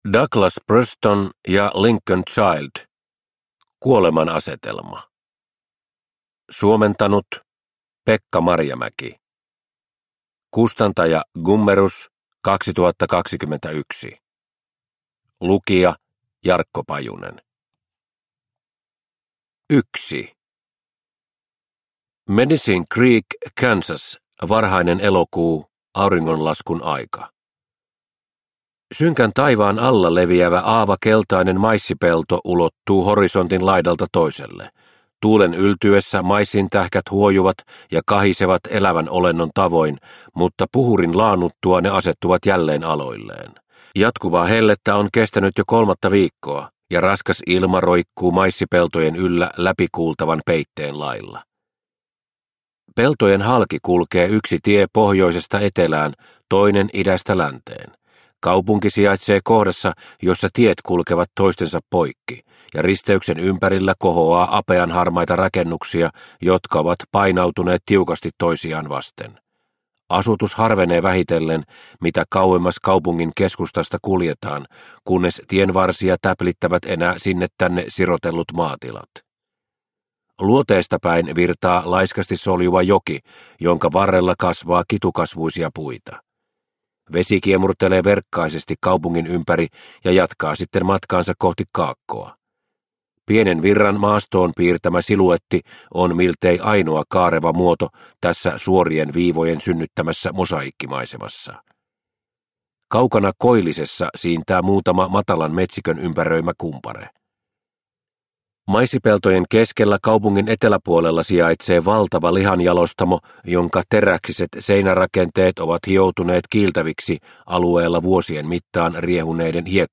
Kuoleman asetelma – Ljudbok – Laddas ner